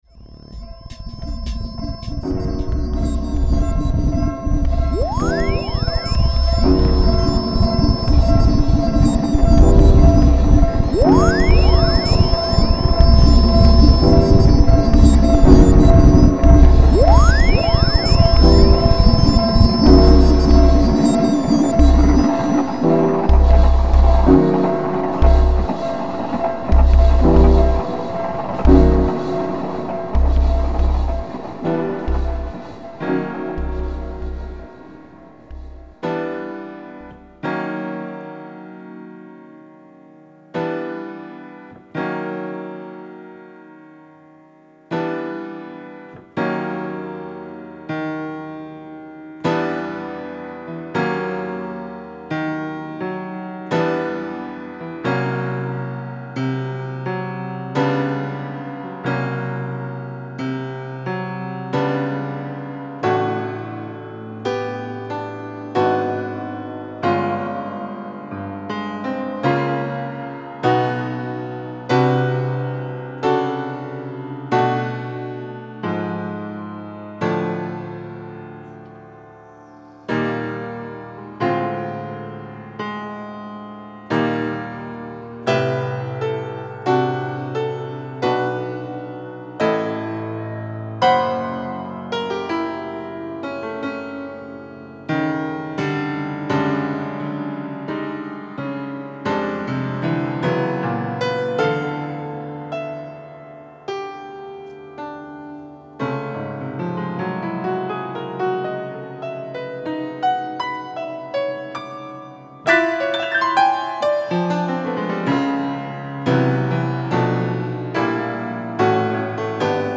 音楽のフリをしていますが、非音楽です。非信号のフリをしていますが、実は信号です。